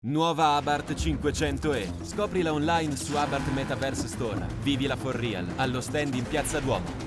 Male
Confident, Engaging, Friendly, Natural, Versatile, Corporate, Deep, Young
Microphone: SE Electronics 2200A / Shure MV7